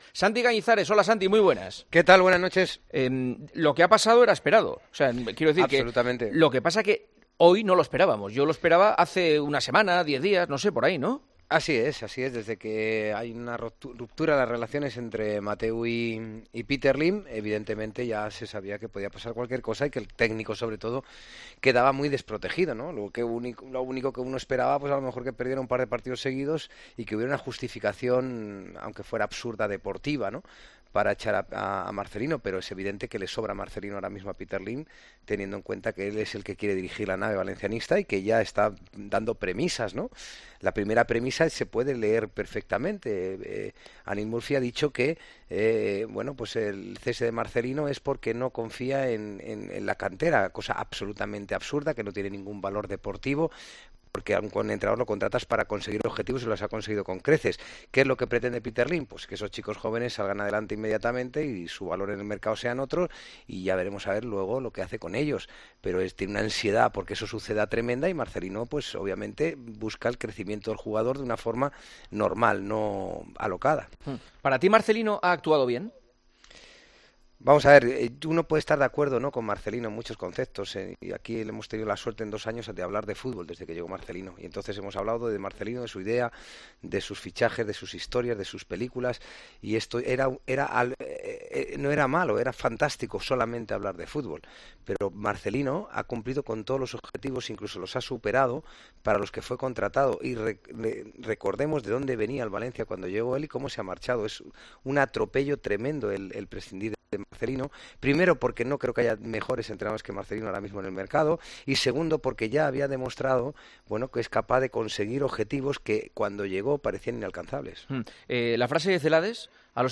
Juanma Castaño habla en El Partidazo de COPE con Santi Cañizares sobre la destitución de Marcelino como entrenador ché y el fichaje de Celades: "Era esperado.